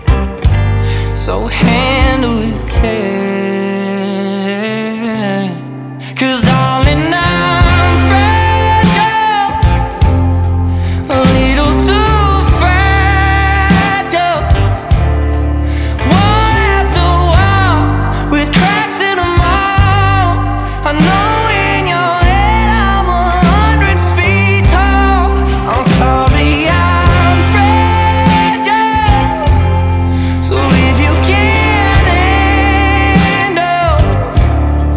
Жанр: Альтернатива / Кантри